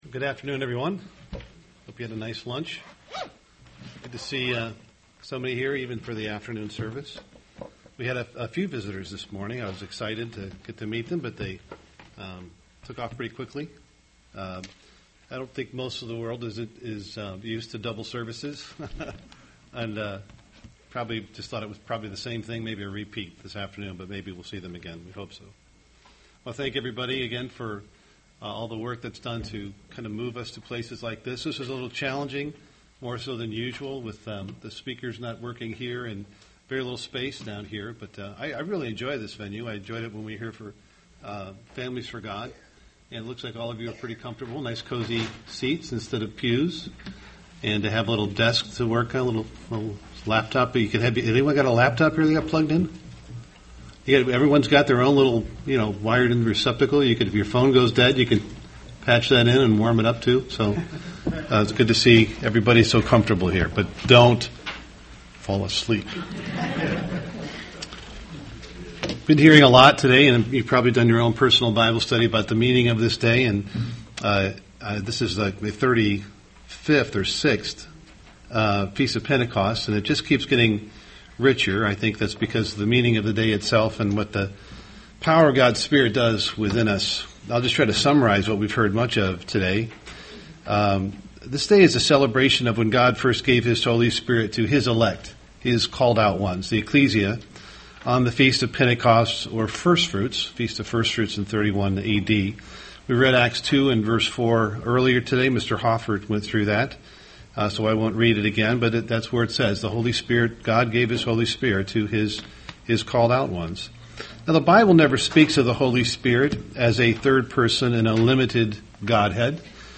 UCG Sermon Holy Spirit Pentecost Studying the bible?